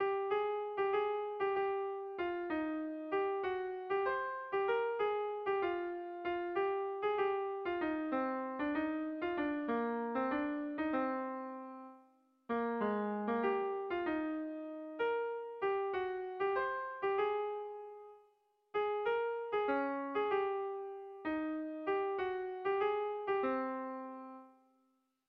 Zortziko txikia (hg) / Lau puntuko txikia (ip)
ABDE